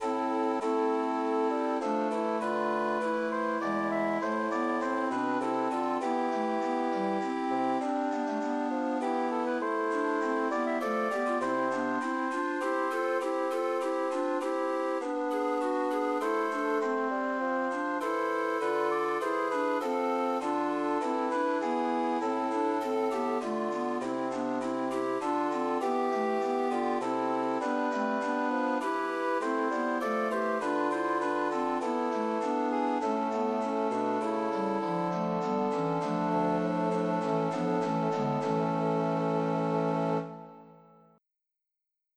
Organ  (View more Intermediate Organ Music)
Classical (View more Classical Organ Music)